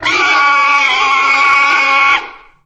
boar_anomaly_1.ogg